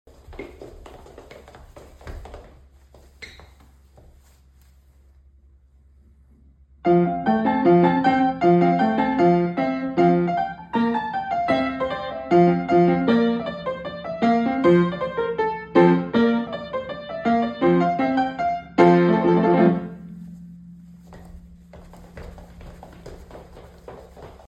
on piano for 4th of July… played upside-down, of course.